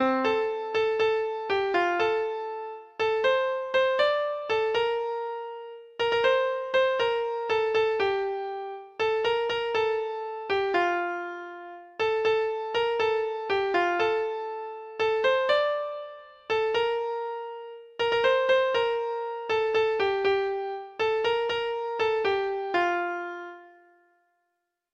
Traditional Trad. John Curtis Treble Clef Instrument version
Folk Songs from 'Digital Tradition' Letter J John Curtis
Traditional Music of unknown author.